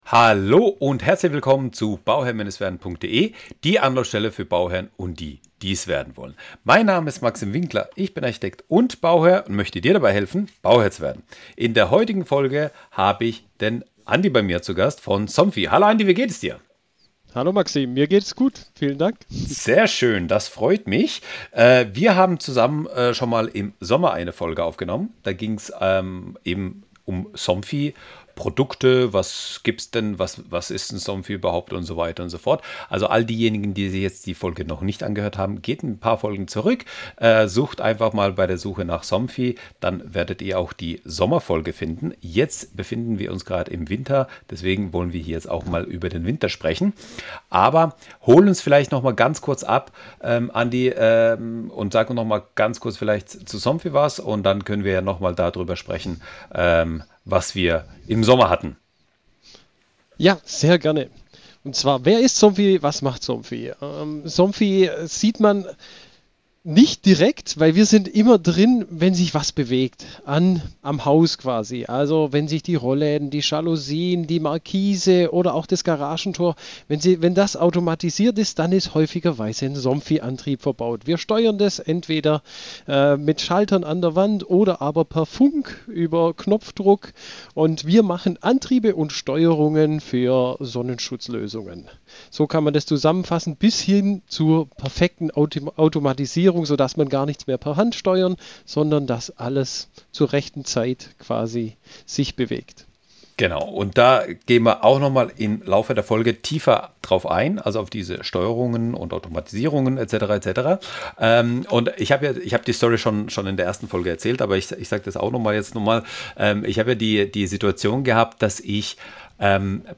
Erfahre, wie intelligente Rollläden, Jalousien, Sensoren und Automatisierung dein Zuhause nicht nur komfortabler, sondern auch effizienter machen. Die Experten teilen eigene Erfahrungen zur Steuerung über App, Fernbedienung und Sensoren, geben Tipps zur Einbindung von Zigbee-Geräten und erklären, wie du durch smarte Planung Förderungen optimal nutzen kannst.